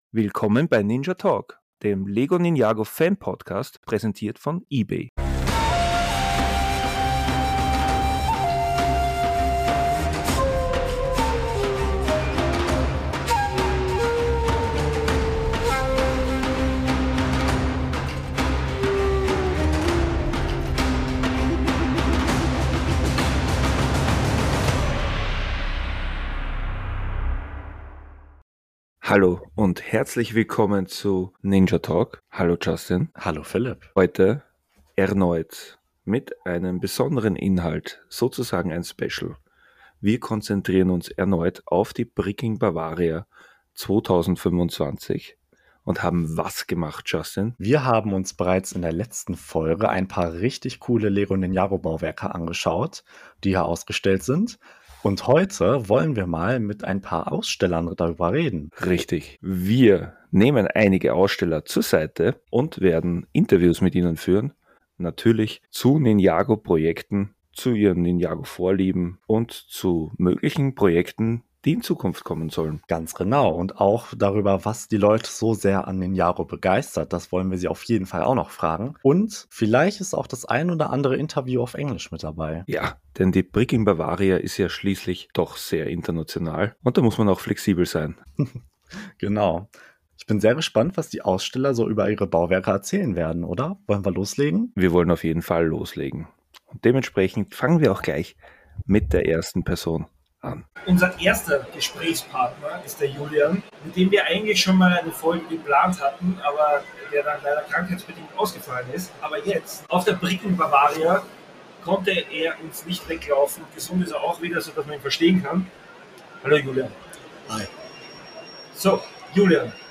Einige von ihnen sind Ninjago-Fans oder lassen sich vom LEGO Thema Ninjago inspirieren. Die Interviews dazu, hört ihr hier!